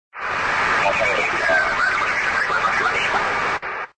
SPolicemanAlarm.ogg